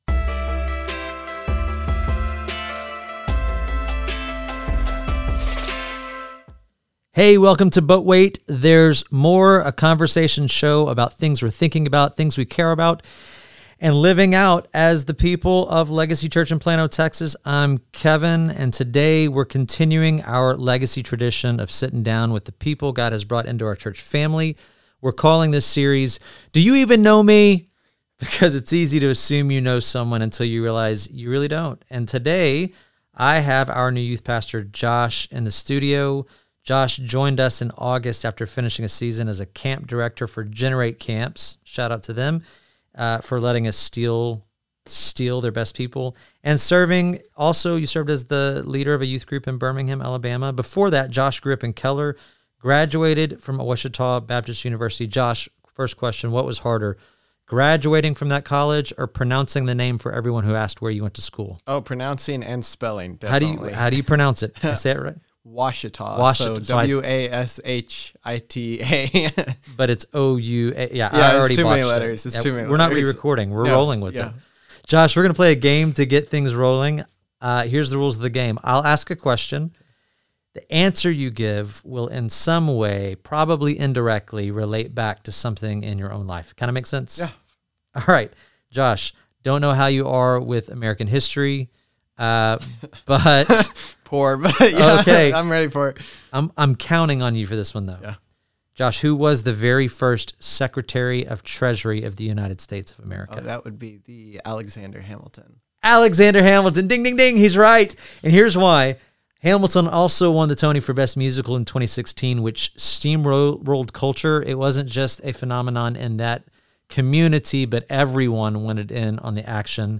This is a conversation show about things that are important to the life and culture of the people of Legacy Church in Plano, TX.